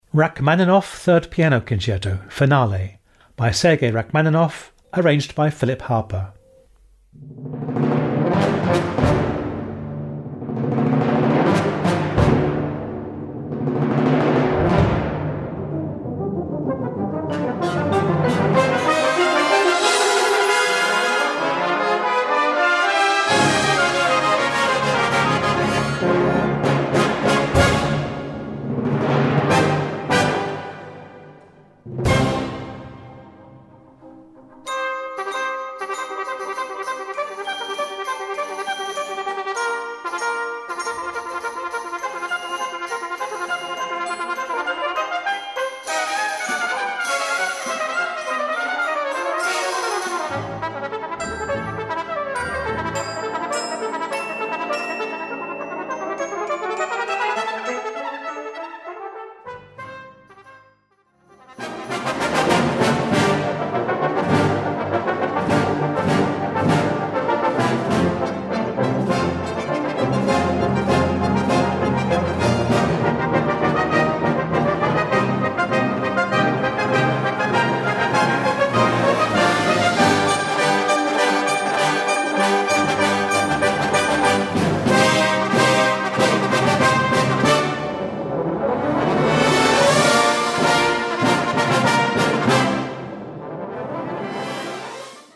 Répertoire pour Brass band - Brass Band